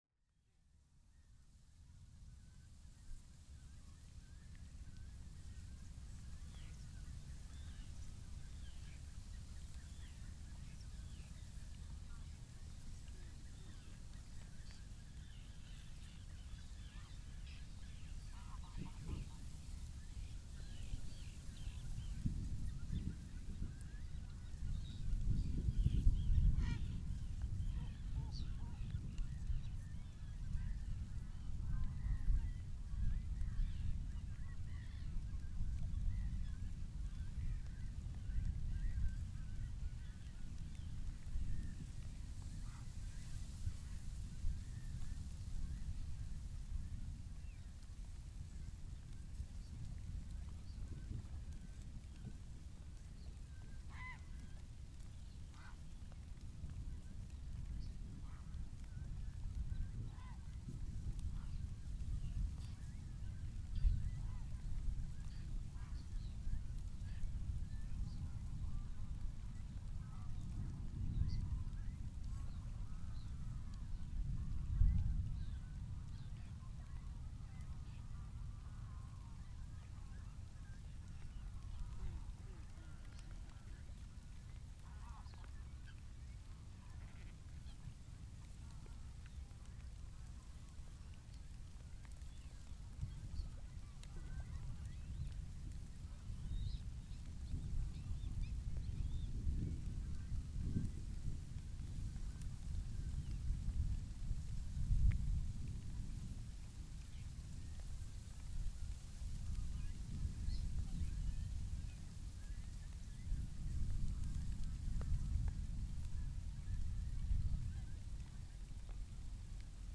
Brandenburg thunderstorm
Occasions to get thunderstorms recordings without man-made noise are rare.
Thus, this is a rather improvised sound recording of the thunderstorm, including some typical bird sounds
of a Brandenburg marshland: White-tailed Eagle, Greylag Goose, Common Tern, Whiskered Tern, Grey Heron,
Great Cormorant, Savi’s Warbler, Mallard, Eurasian Coot, Gadwall, Black-headed Gull…
PFR15209, 180722, thunderstorm, Germany